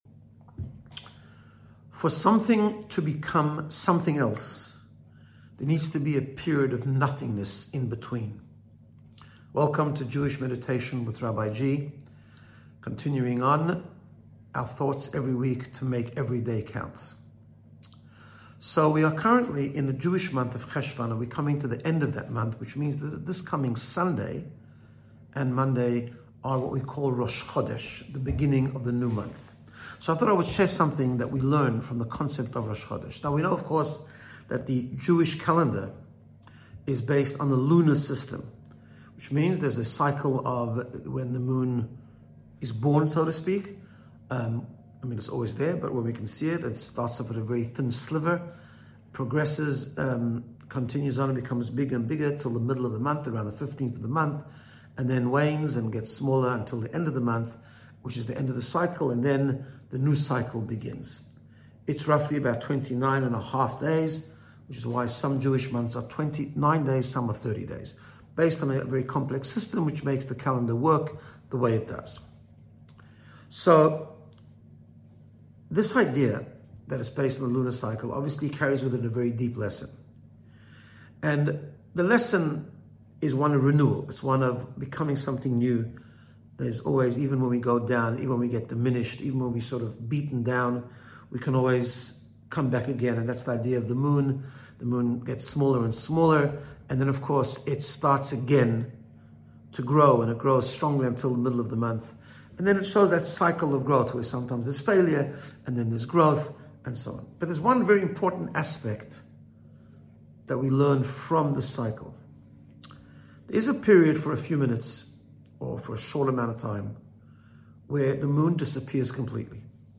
Making Each Day Count: Episode 14 - Jewish Meditation